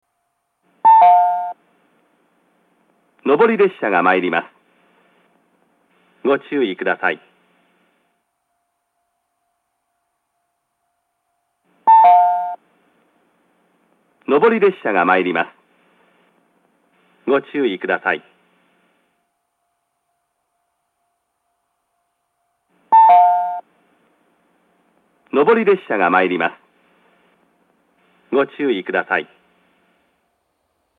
ushirogata-nobori-sekkinn.mp3